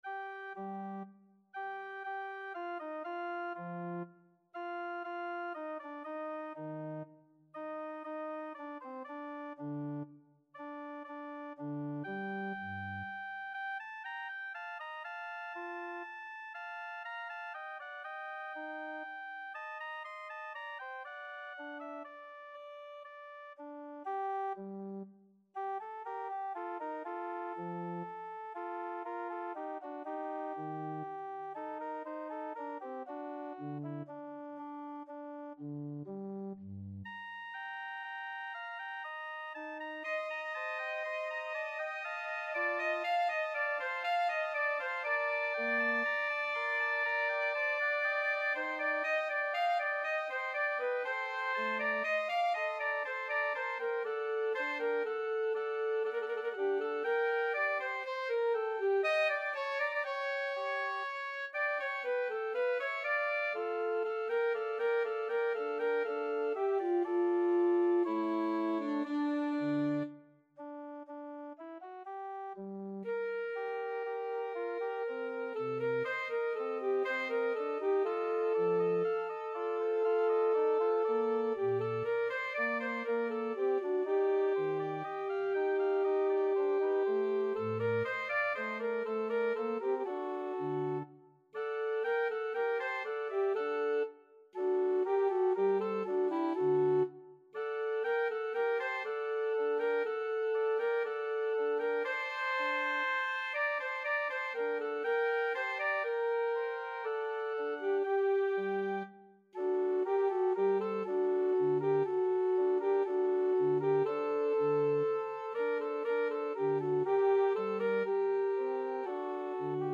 Alto Saxophone version
Alto Saxophone
3/4 (View more 3/4 Music)
Classical (View more Classical Saxophone Music)